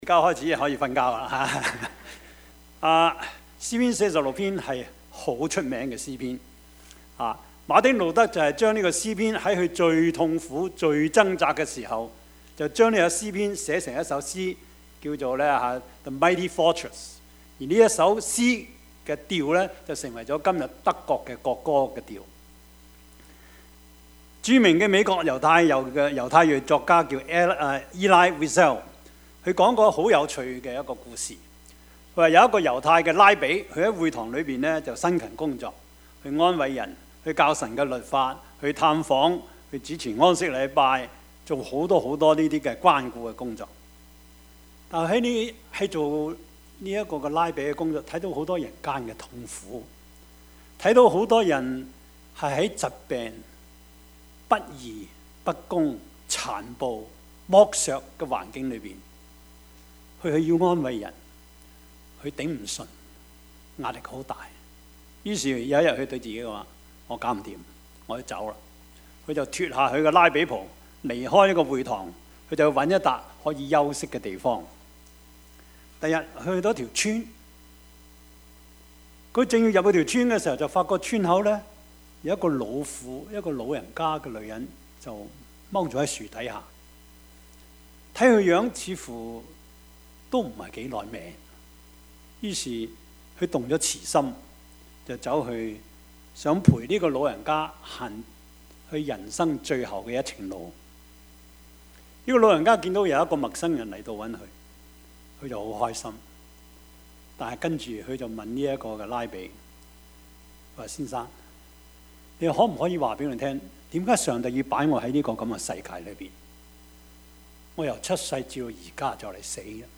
Service Type: 主日崇拜
Topics: 主日證道 « 什麼榜樣？